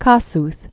(kŏsth, kôsht), Lajos 1802-1894.